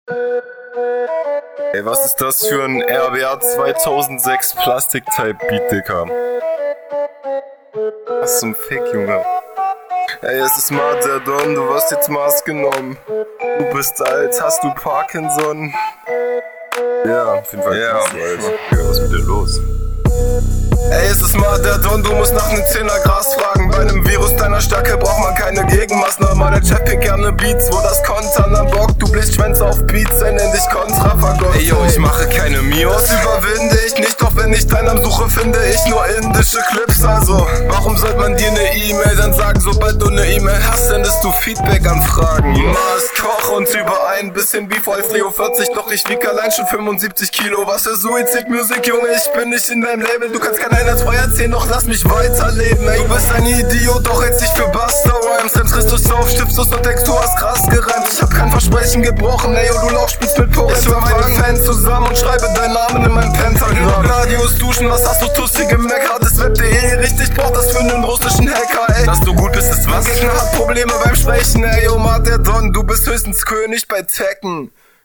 Deine Abmische ist ebenfalls nicht so geil. Viel zu viel Höhen drinn.
Leider hast du hier einige Schwierigkeiten mit dem Beat.